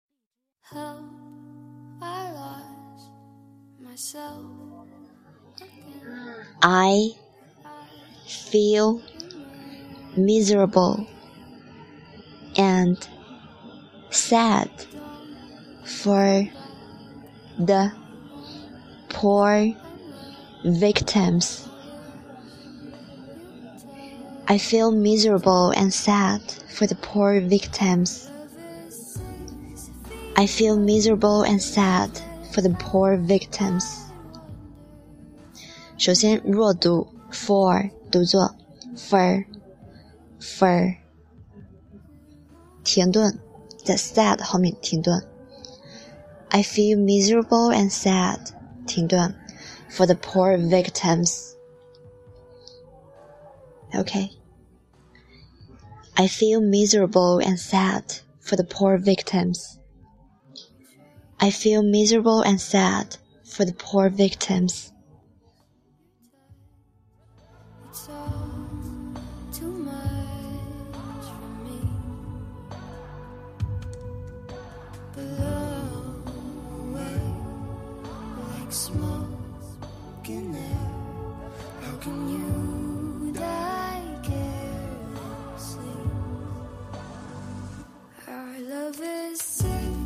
1.弱读for
2.停顿sad
3.吐字清楚，发音饱满